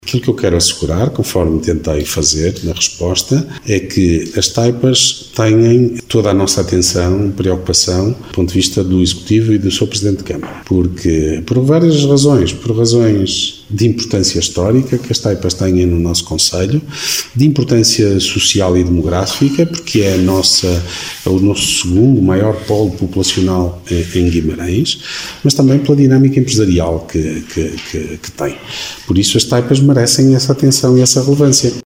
Declarações de Ricardo Araújo, presidente da Câmara Municipal de Guimarães.